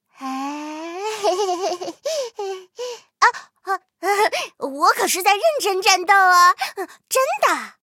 M10狼獾MVP语音.OGG